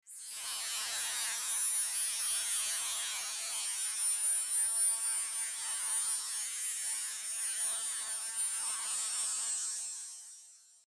Download Heavy Machinery sound effect for free.
Heavy Machinery